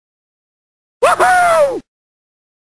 homer_simpson_woohoo_sound_effect_lizogvm.mp3